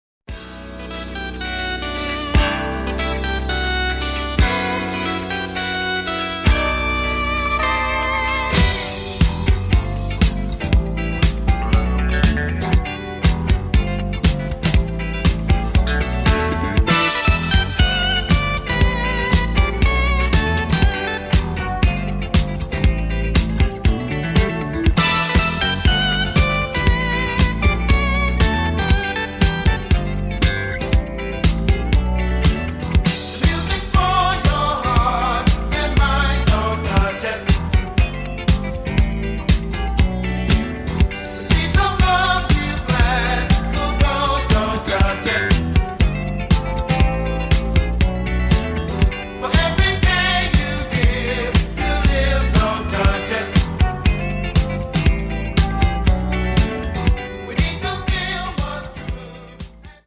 Obscure disco gold comp